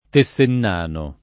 [ te SS enn # to ]